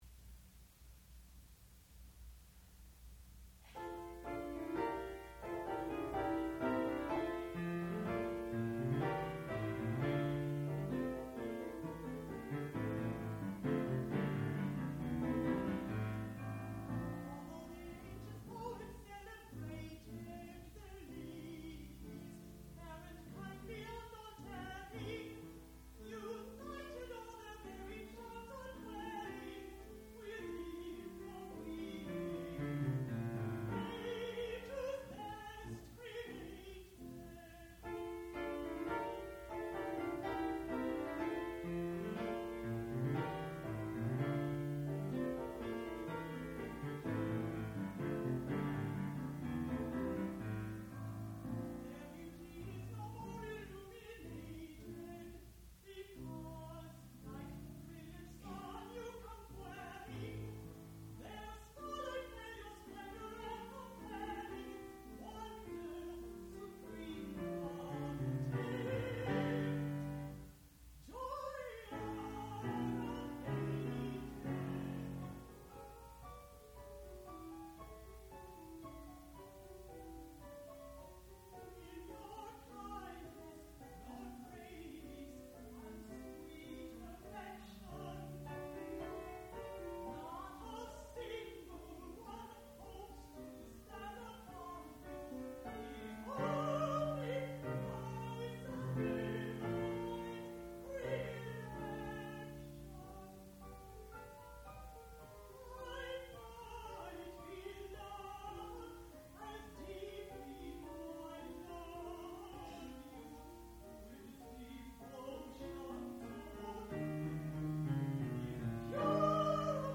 sound recording-musical
classical music
piano
Graduate Recital
mezzo-soprano